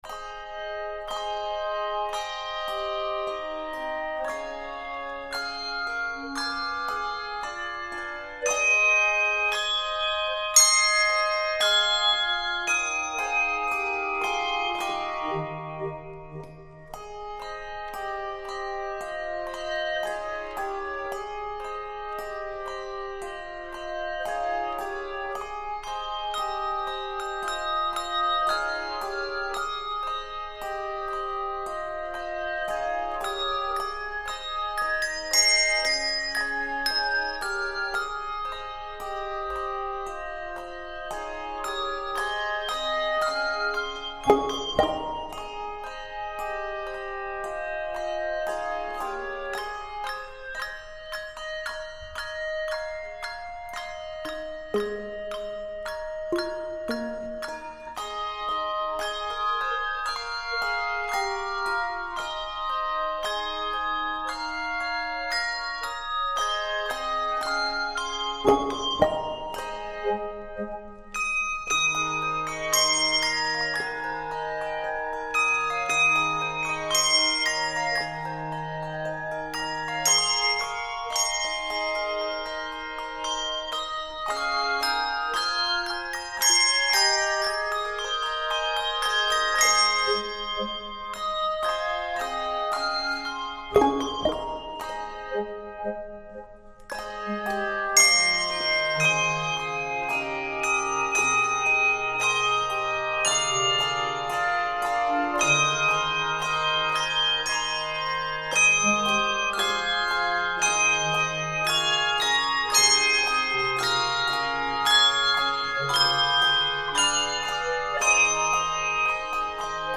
This stirring arrangement